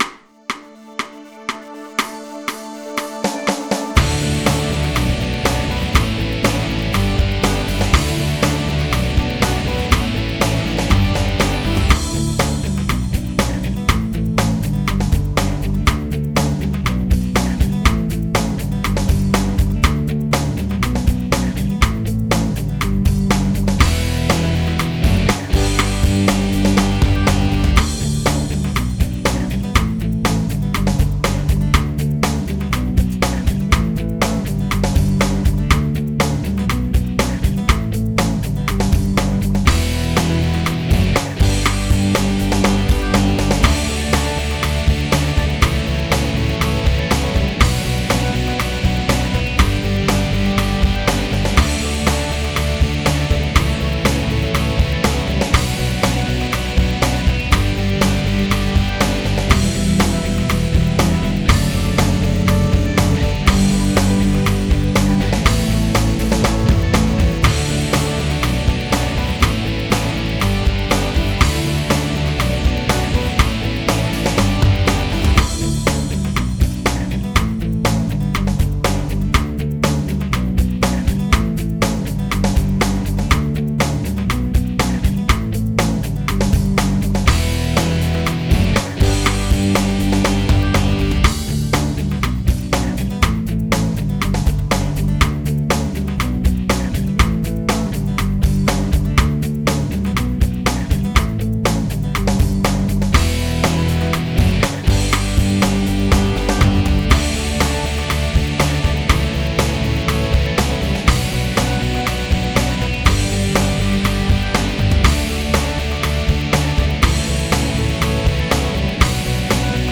Click Track MP3's
today-is-the-day-click-AE07XjLrEvcKbrjq.wav